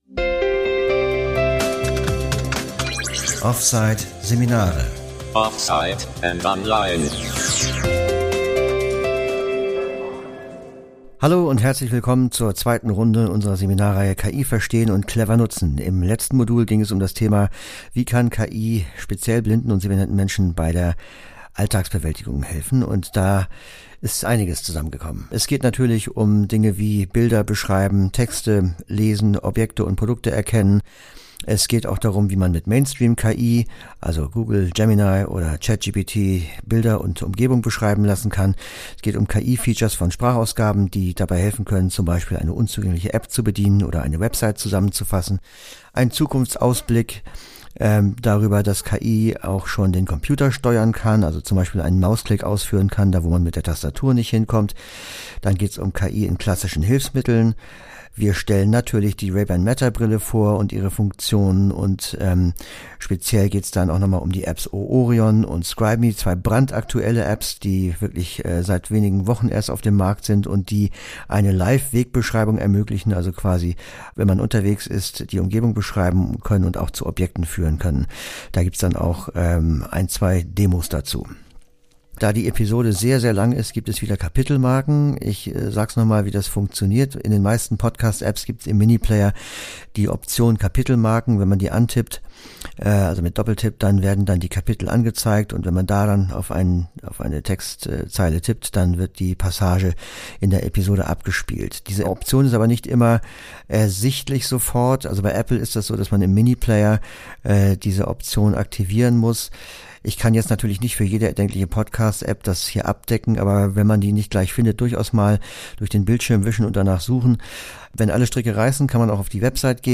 In Teil 2 unserer Seminarreihe